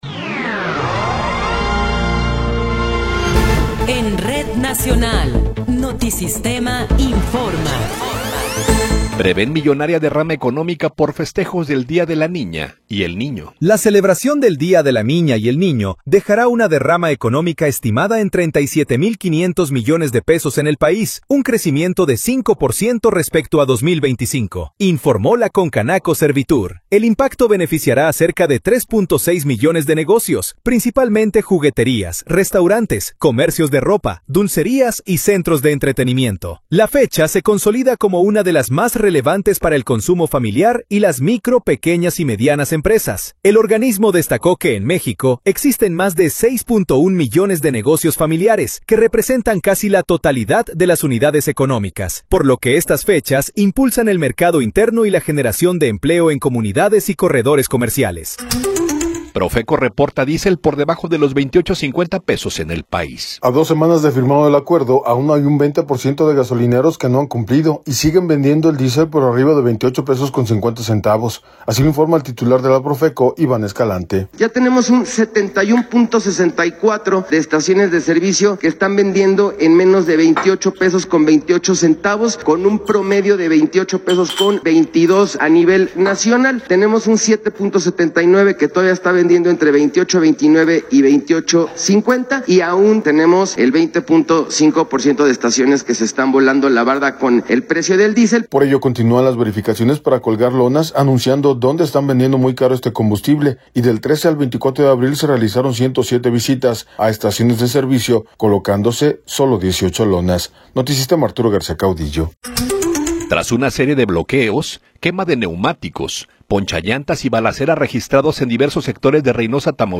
Noticiero 10 hrs. – 27 de Abril de 2026